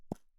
Added ball sfx
grass5.wav